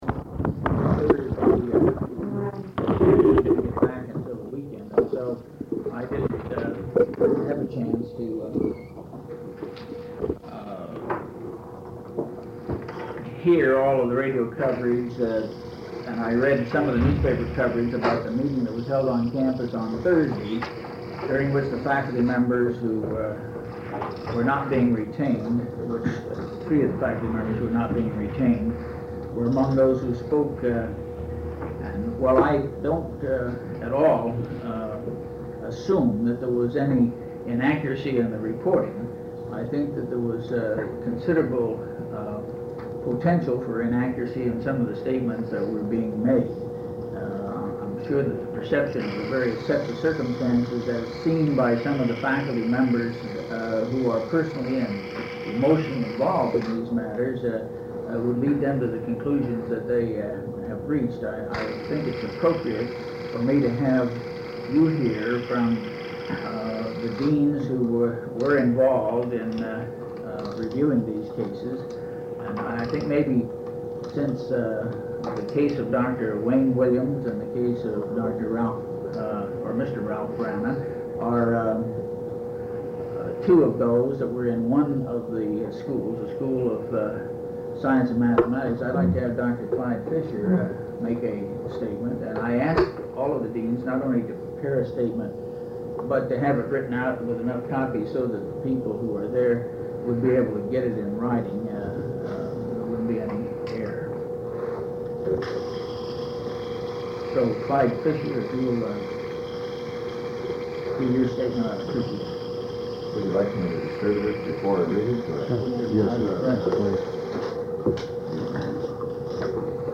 press conference on the non-retention of instructors at Cal Poly